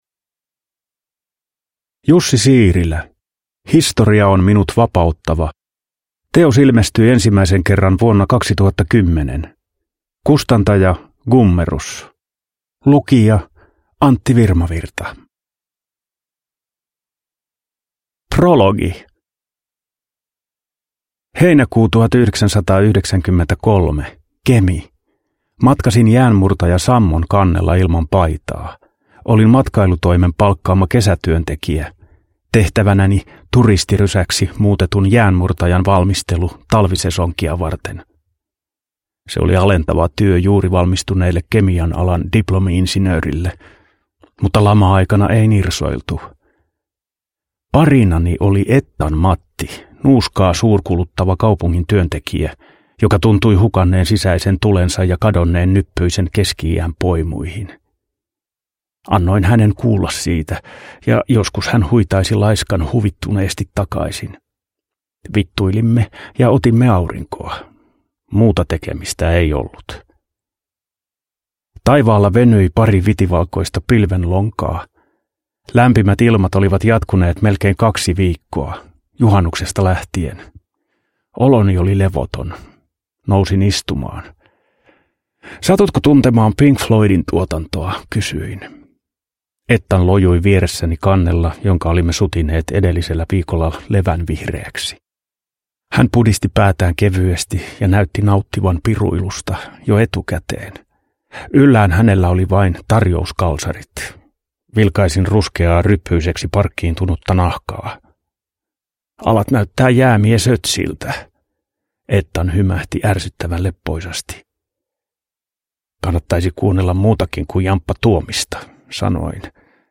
Historia on minut vapauttava – Ljudbok – Laddas ner
Uppläsare: Antti Virmavirta